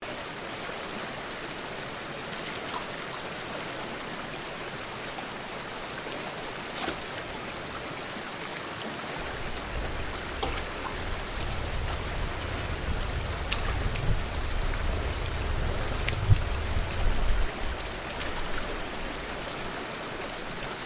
Free Nature sound effect: Rushing White Water.
Rushing White Water
Rushing White Water.mp3